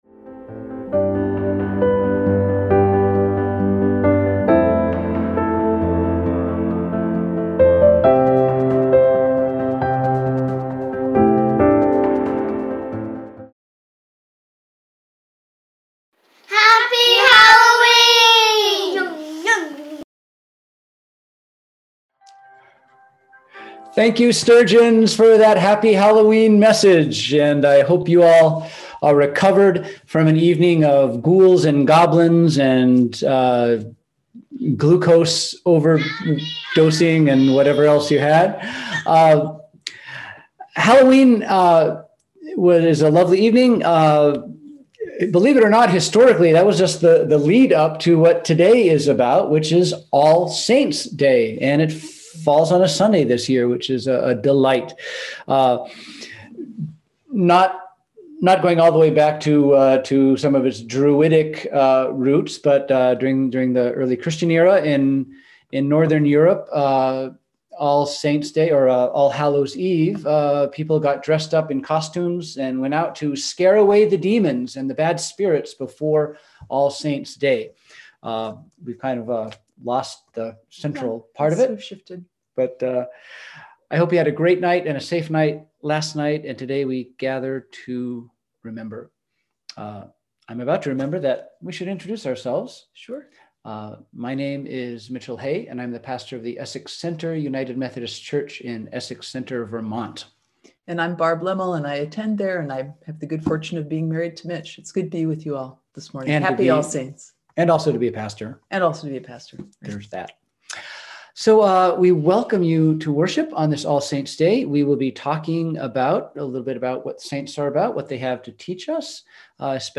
We held virtual worship on Sunday, November 1, 2020 at 10am.